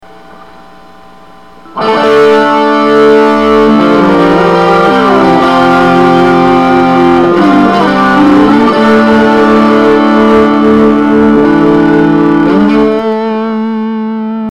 57.Misc.Guitar Riff 4.0.mp3